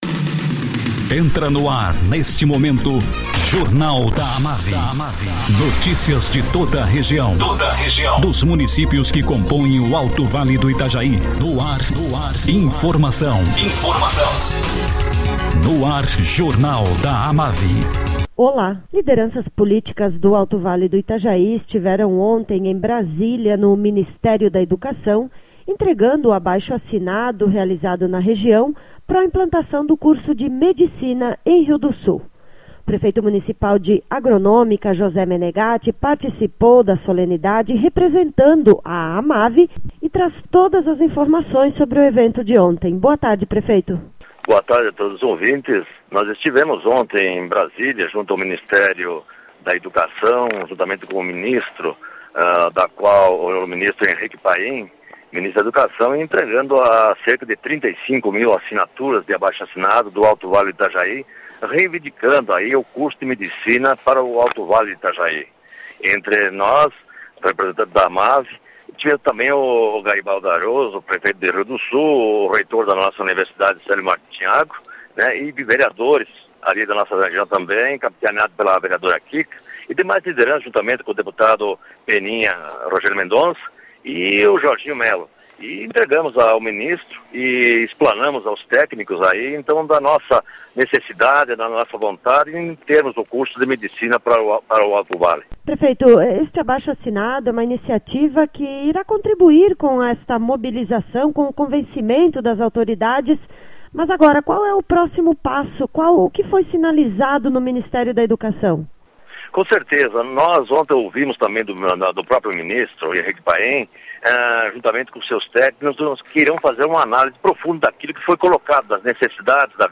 Prefeito de Agronômica, José Menegatti, fala sobre o evento realizado ontem em Brasília, onde lideranças políticas do Alto Vale entregaram o abaixo-assinado para implantação do curso de medicina em Rio do Sul.